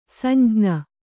♪ samjña